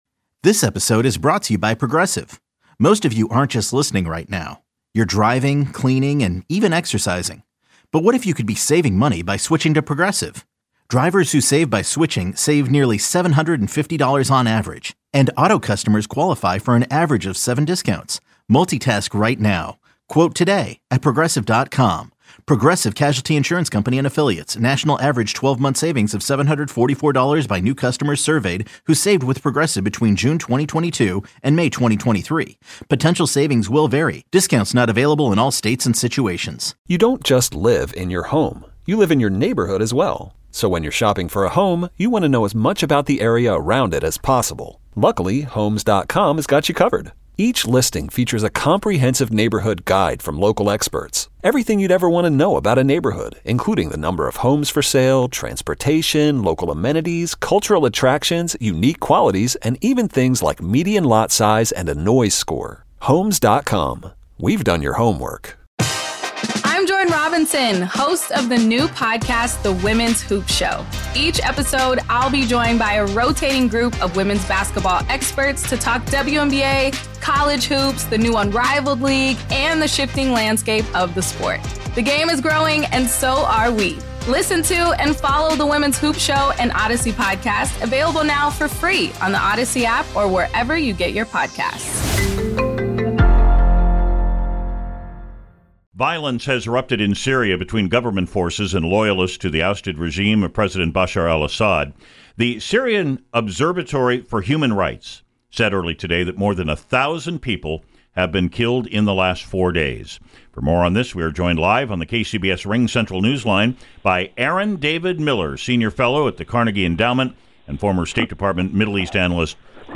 spoke with Aaron David Miller, senior fellow at the Carnegie Endowment and former State Department Middle East analyst and negotiator.